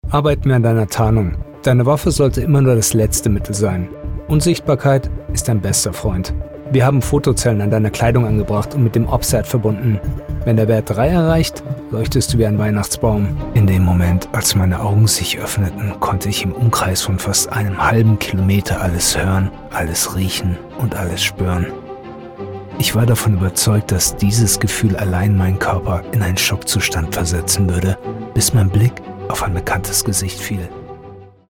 character, foreign-language, german, gritty, real, serious, tough